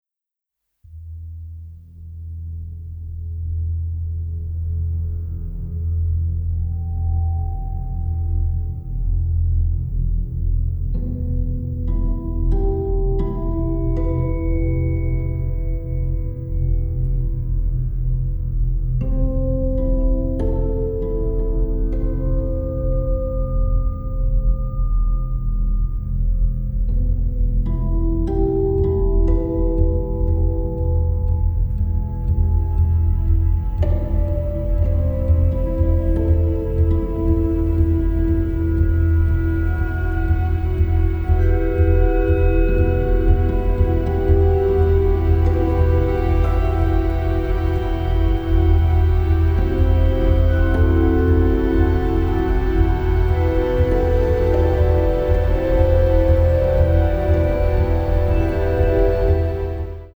a very emotional and sensitive score
especially cello, harp and guitar.